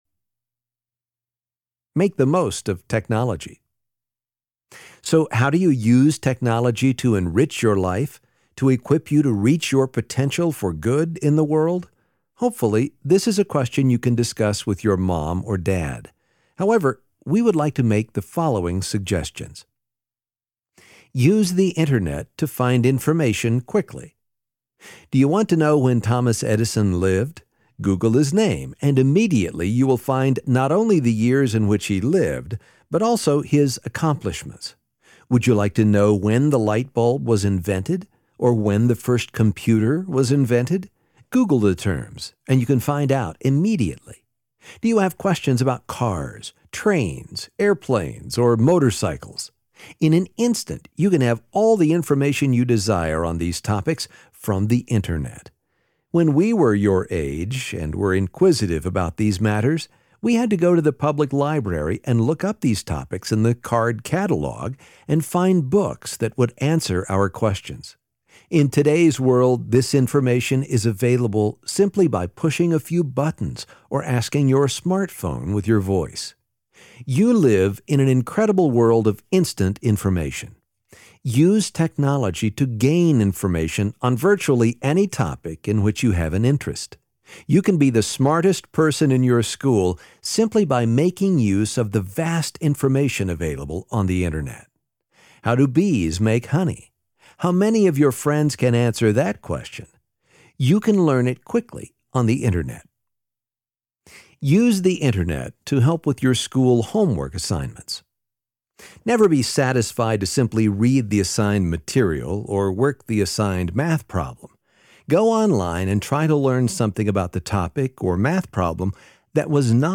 Choose Greatness Audiobook
Narrator
3.42 hrs. – Unabridged